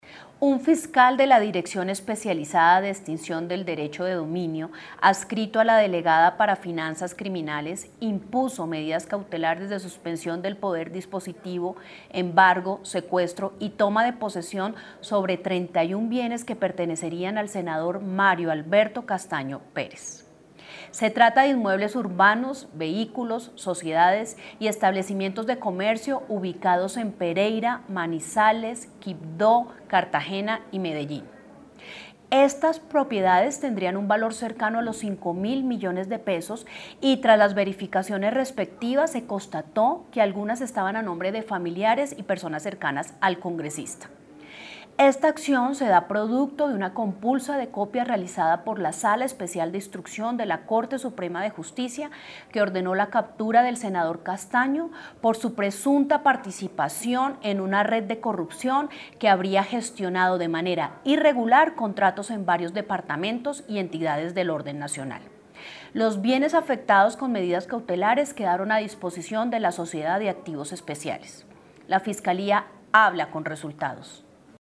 Audio de la Fiscal Delegada para las finanzas criminales, Luz Ángela Bahamón:
Audio-Fiscal-Delegada-para-las-finanzas-criminales-Ángela-Bahamón.mp3